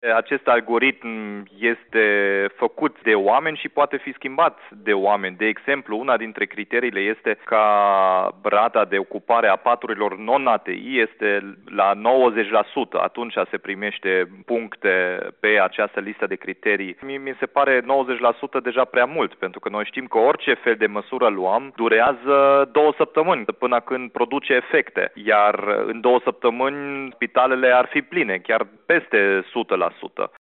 În replică, primarul orașului, Domic Fritz, spune că aceste criterii ar trebui revizuite: